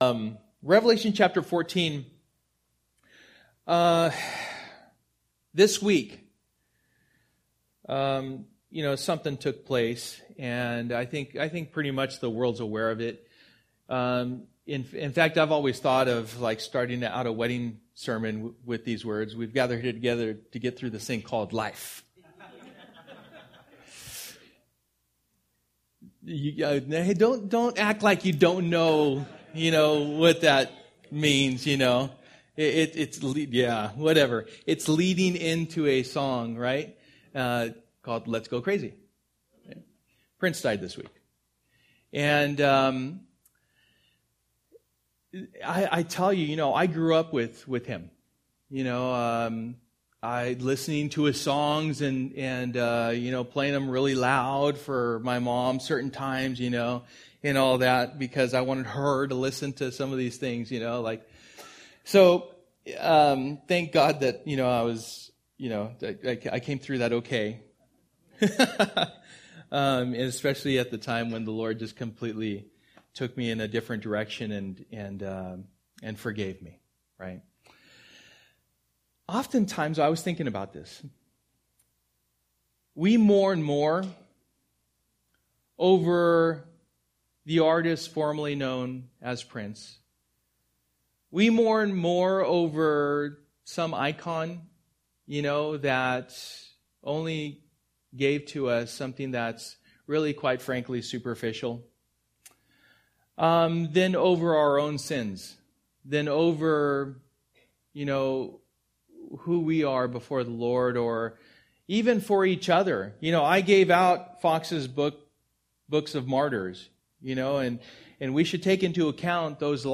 Passage: Revelation 14:1-20 Service: Sunday Morning